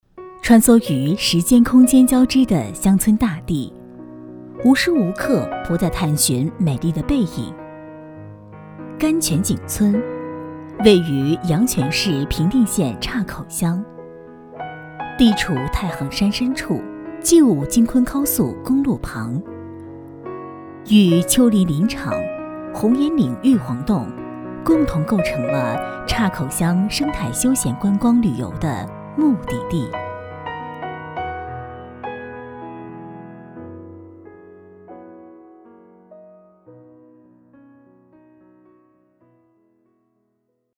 纪录片-女3-景点纪录片.mp3